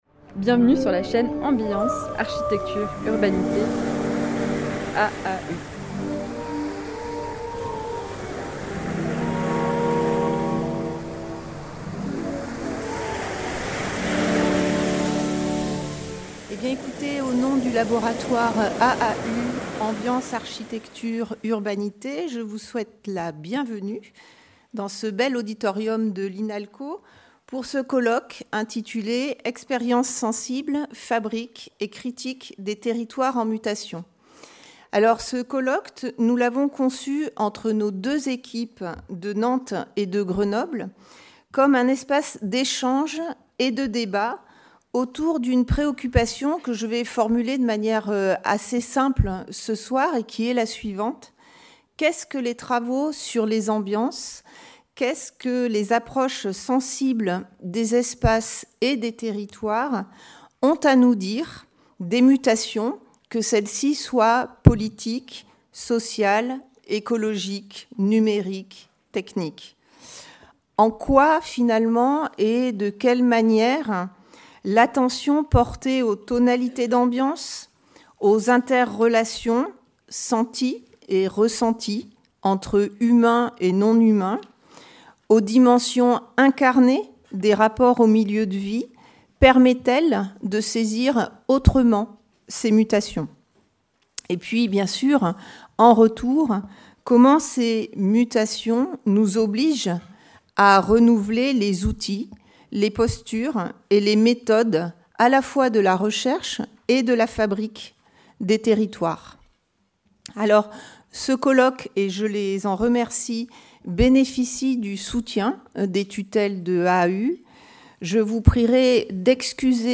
Ouvertures du colloque par son organisatrice et par les représentantes du CNRS et le Ministère de la Culture